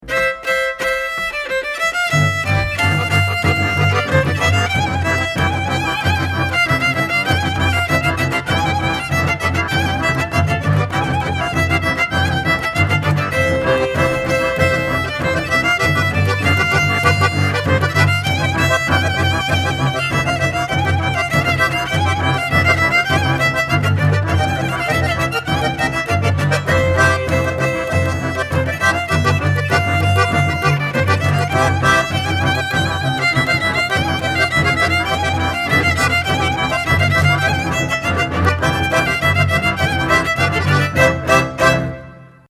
Violin
Button Accordion, Piano
Bass Cello, Tilinca
Genre: Klezmer.